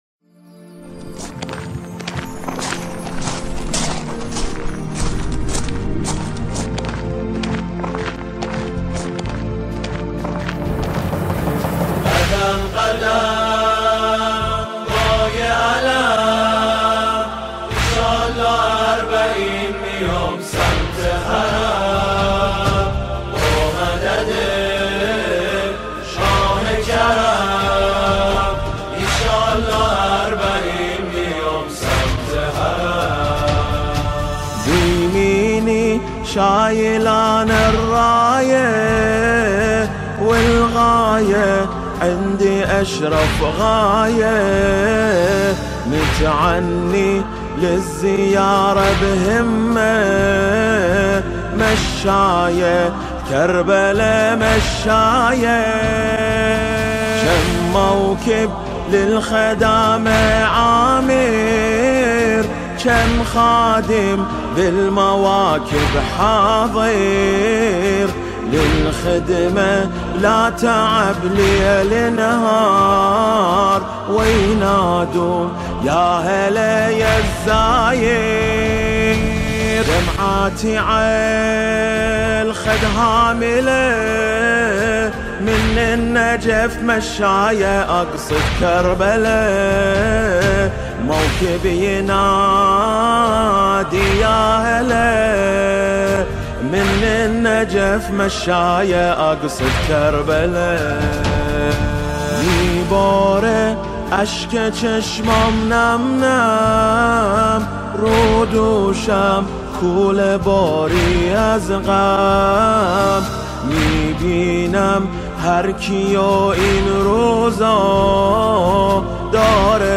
این نجوای زیبا رو هم با گوش دل بشنوید و زمزمه کنید و برای اربعینی ها بفرستید خیلی قشنگ است .